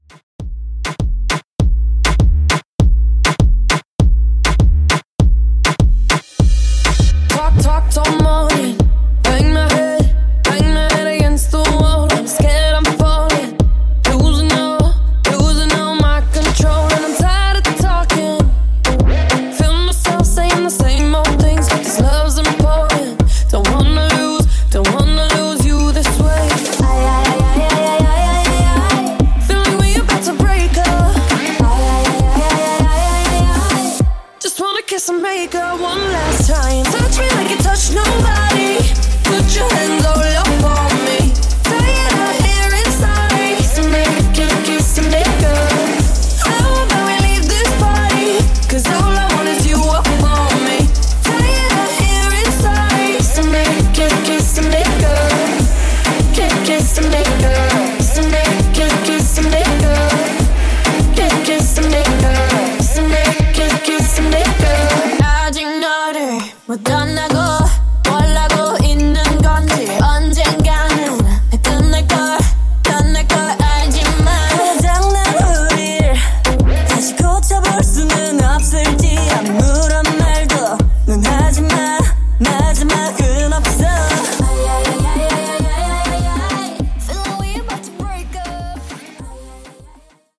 Moombah)Date Added